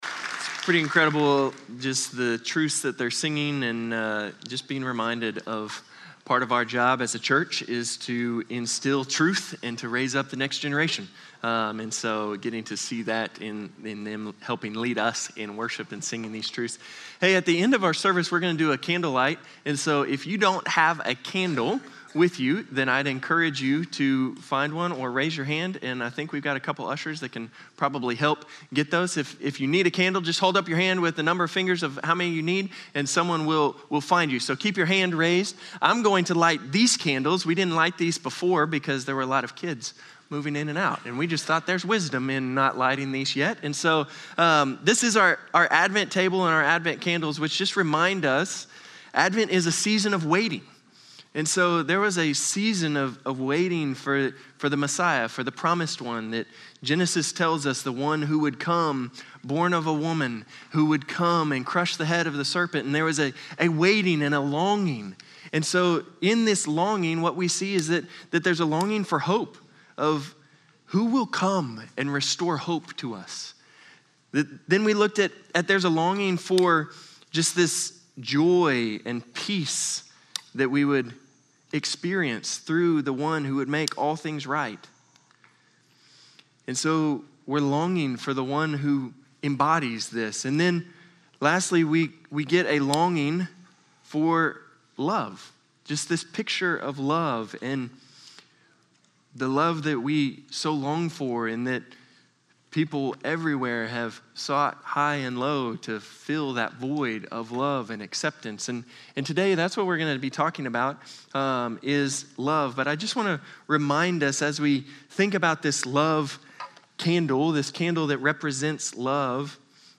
Norris Ferry Sermons Dec. 21, 2025 -- Advent: Love + Christ -- John 3:16-21 Dec 21 2025 | 00:27:26 1x 00:00 / 00:27:26 Subscribe Share Spotify RSS Feed Share Link Embed